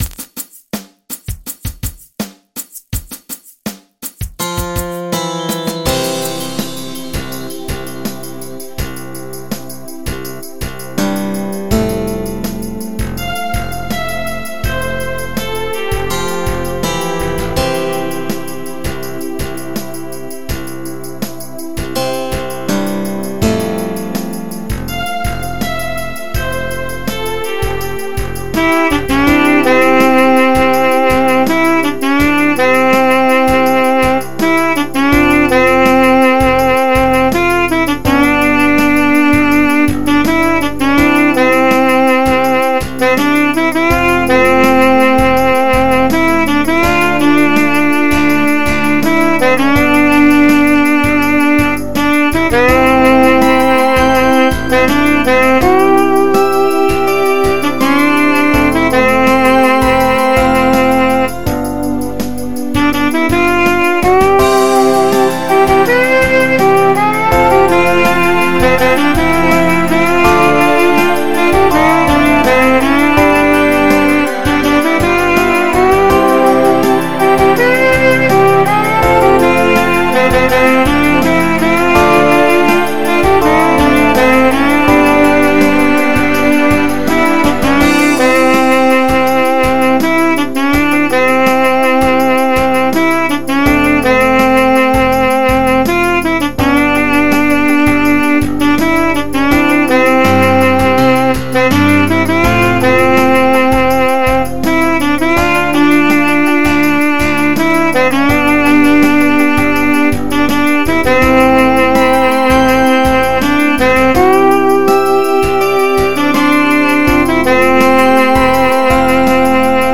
MP3 (Converted)
is a love song